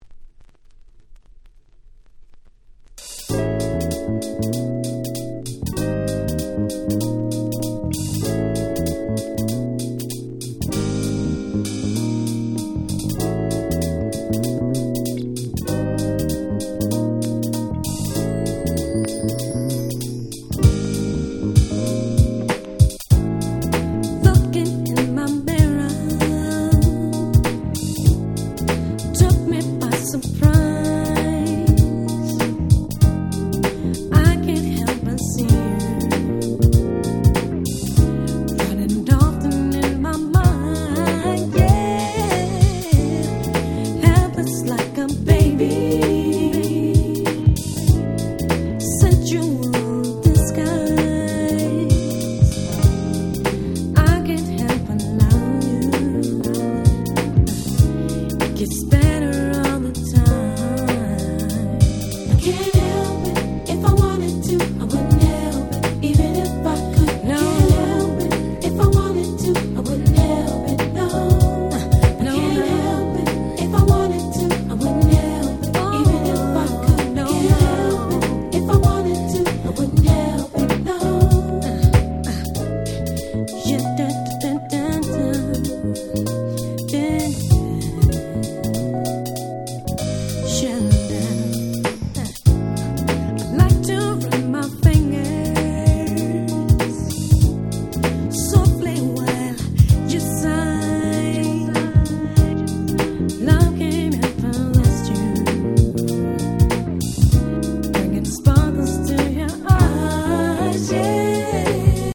98' Very Nice R&B / Neo Soul !!